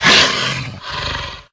gamedata / sounds / monsters / pseudodog / psy_attack_0.ogg
psy_attack_0.ogg